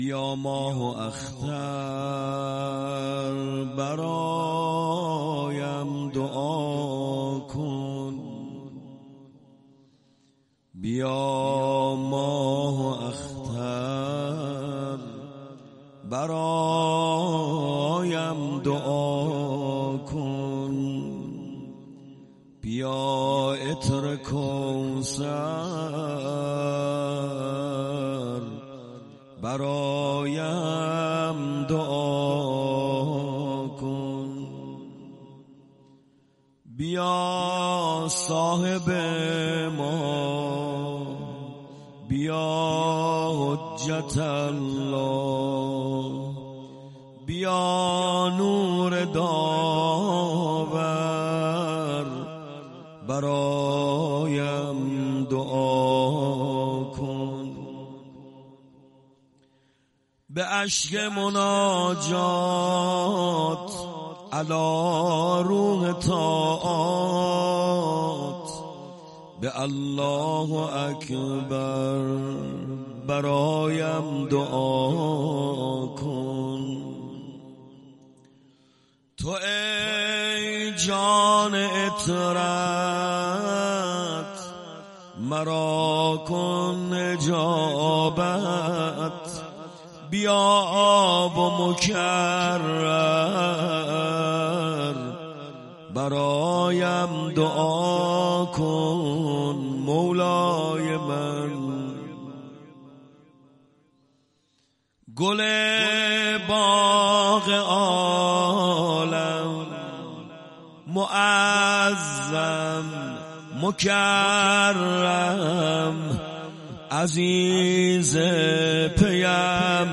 روضه.تاری.mp3